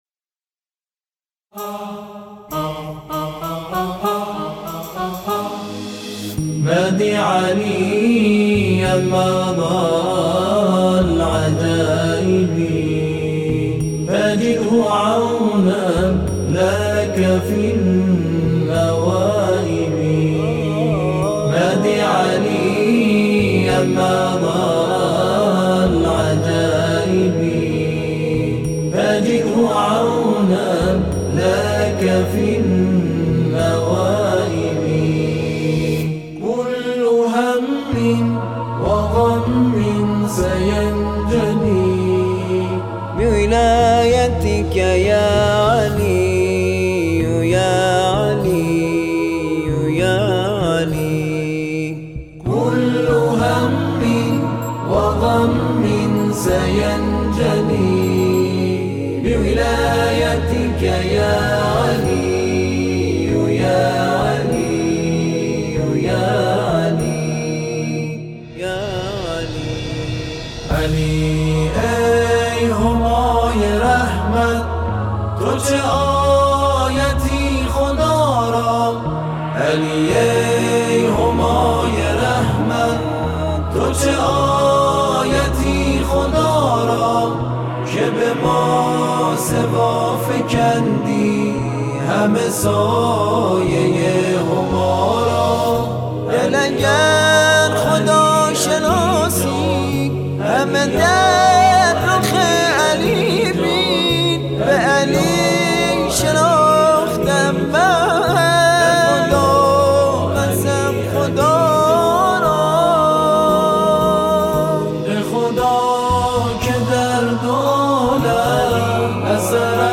گروه تواشیح و هم‌خوانی